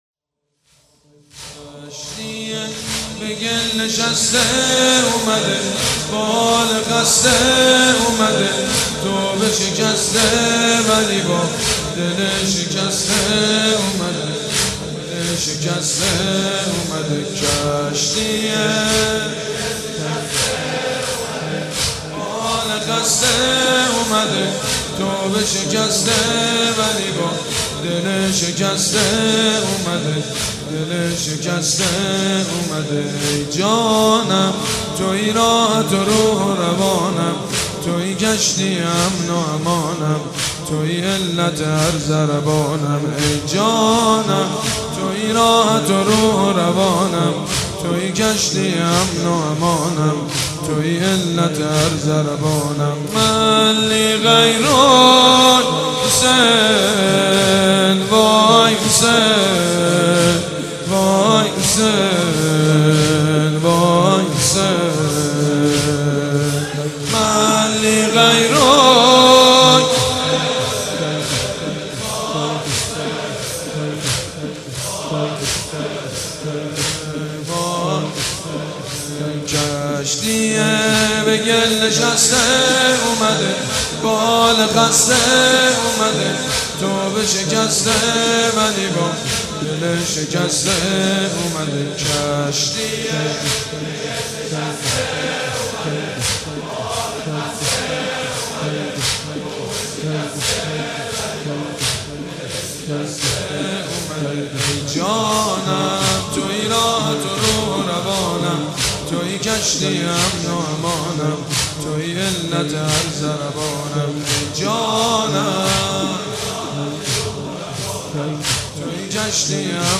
روضه
روضه سیدمجید بنی‌فاطمه